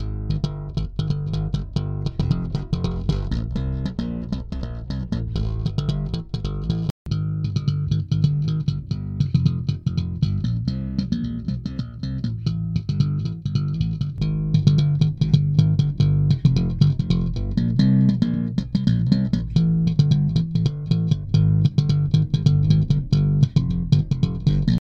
ベースのライン＋マイク録り
下のデータは、ライン、マイク、ライン＋マイクと順に入っています。ライン録りのクリアな輪郭に、マイク録りの低音が効いた暖かな音を足す、という感じですね。